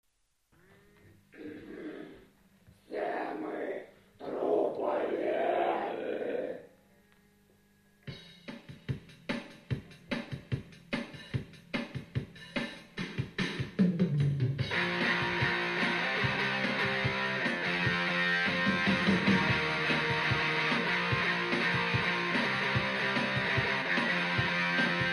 Запись крайне трушная...
keyboard, percussions, vocal, backvocal.
guitar, vocal, backvocal.